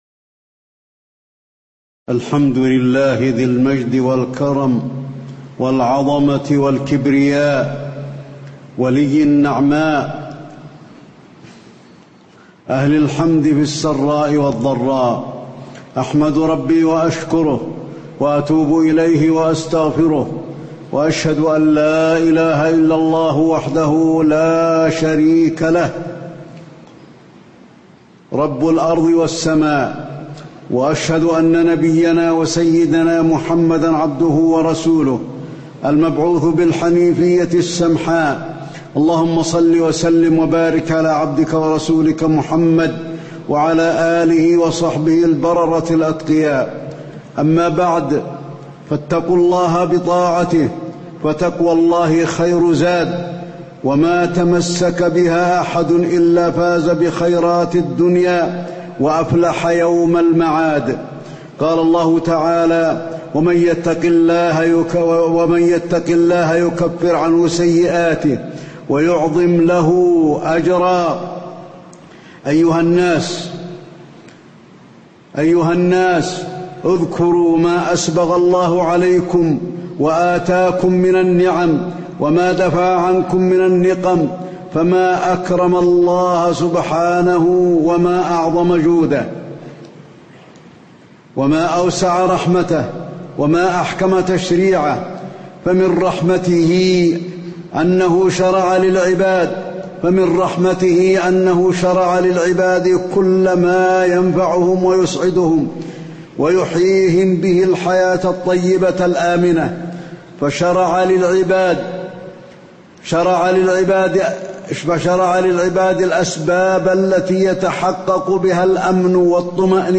تاريخ النشر ٢ ذو القعدة ١٤٣٧ هـ المكان: المسجد النبوي الشيخ: فضيلة الشيخ د. علي بن عبدالرحمن الحذيفي فضيلة الشيخ د. علي بن عبدالرحمن الحذيفي نعمة الأمن وأسبابه